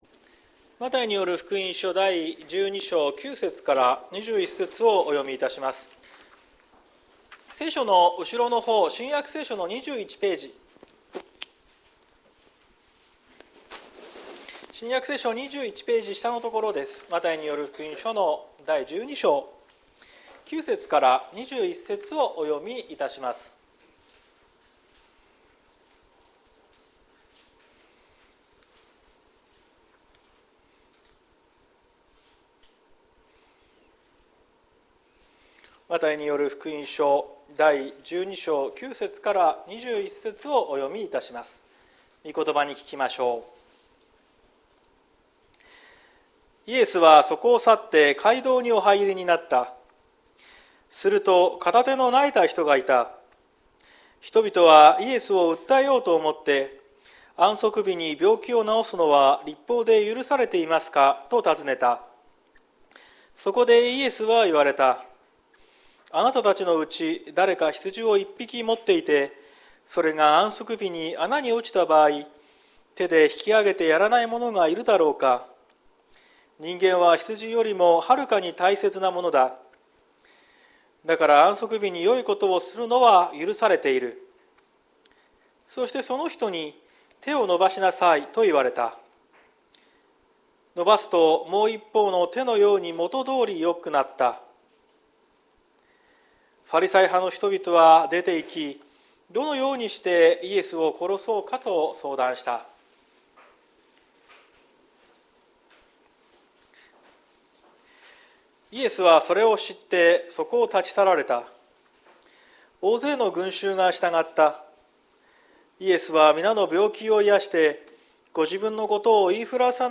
2024年03月03日朝の礼拝「手を差し伸べるイエス」綱島教会
綱島教会。説教アーカイブ。